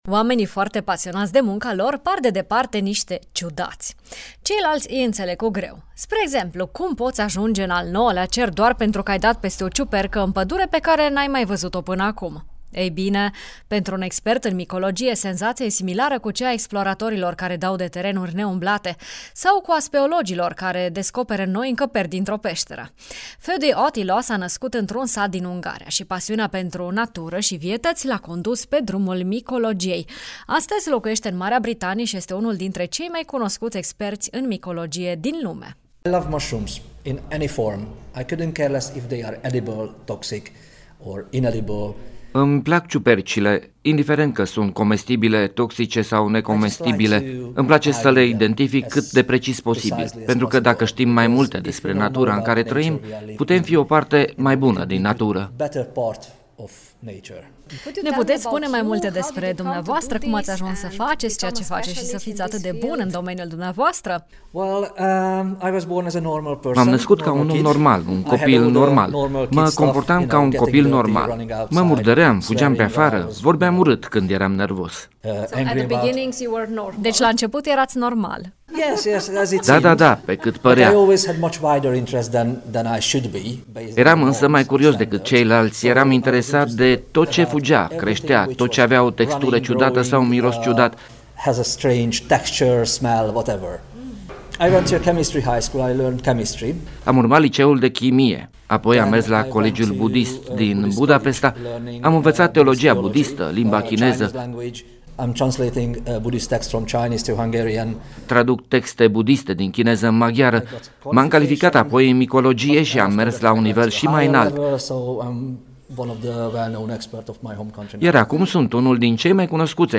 10-nov-BDF-Micolog-cu-traducere.mp3